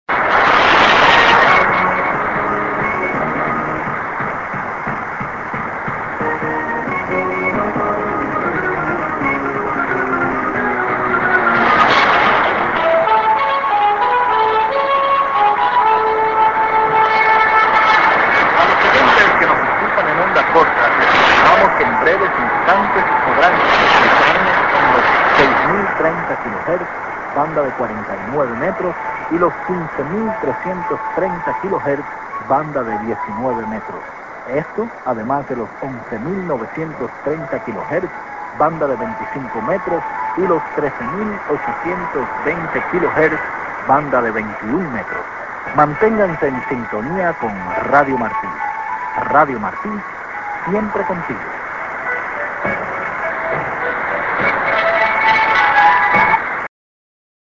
End ST->ID+SKJ(man)